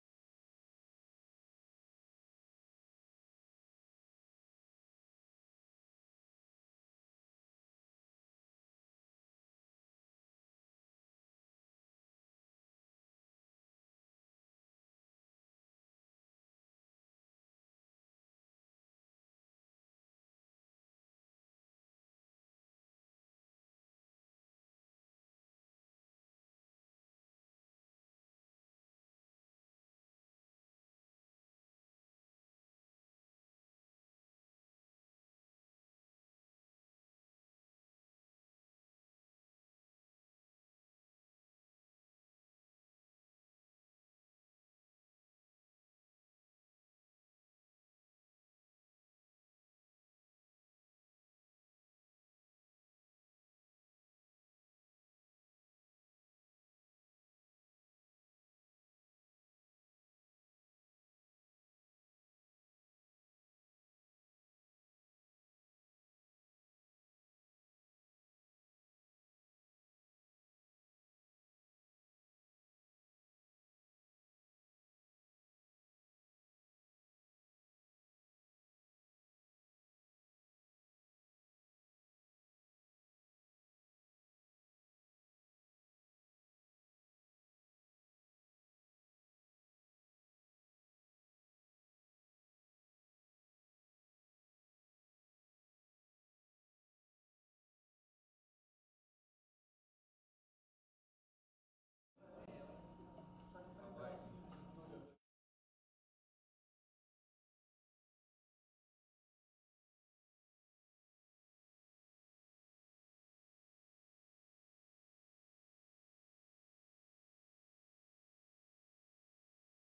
3° SESSÃO SOLENE DIA 24 DE NOVEMBRO DE 2025